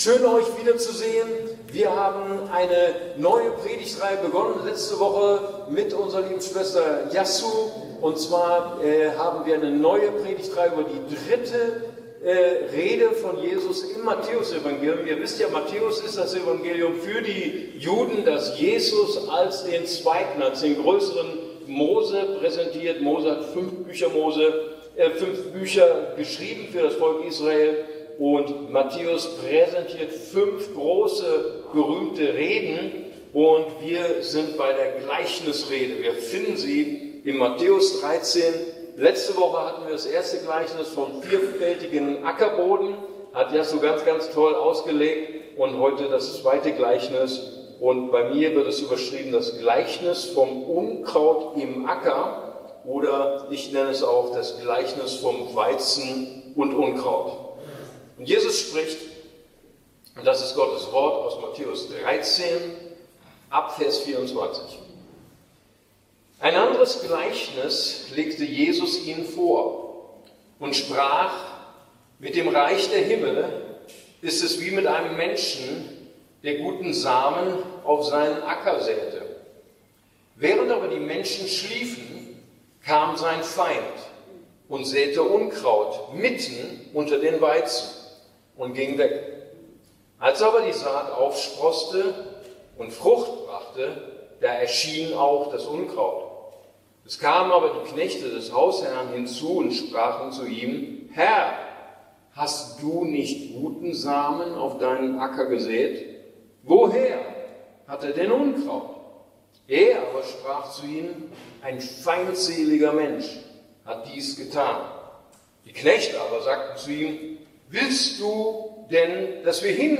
ICB Predigtreihe: Gleichnisse Jesu – Verborgene Schätze Teil 2: Gleichnis vom Unkraut im Acker / Gleichnis vom Weizen und Unkraut